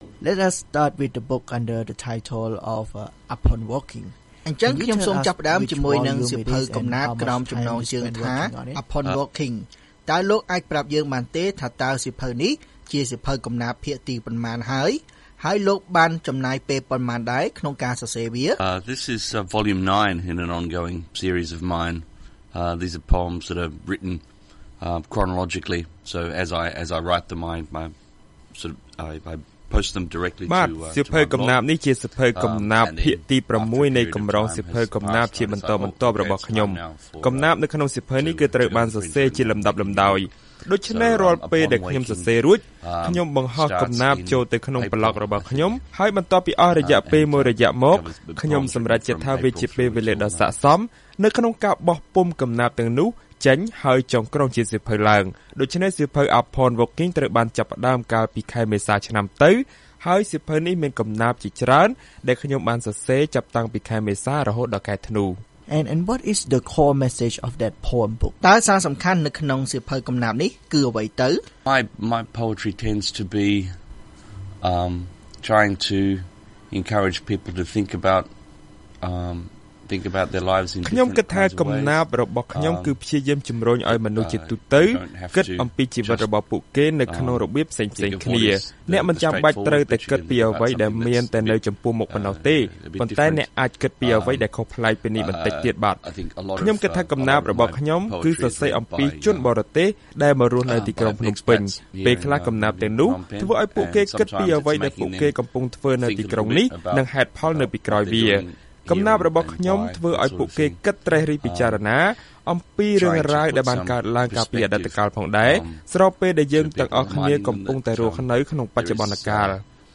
បទសម្ភាសន៍VOA